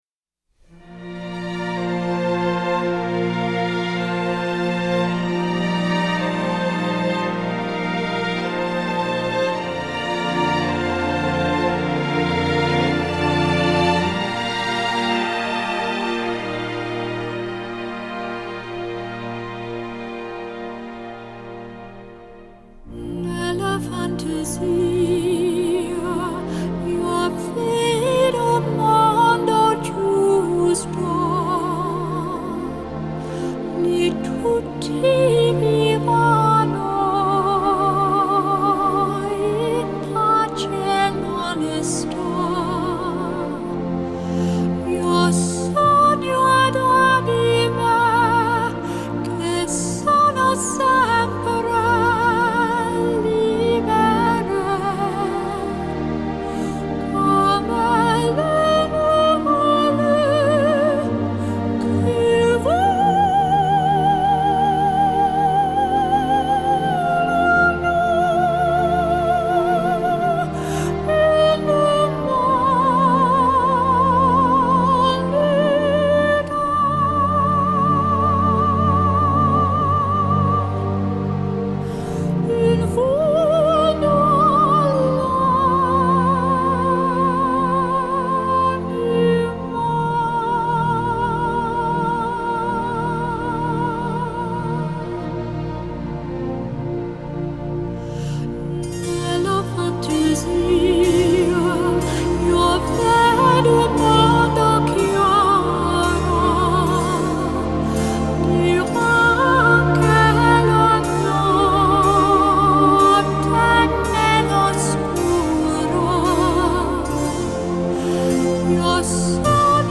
Жанр: Classical Crossover, Pop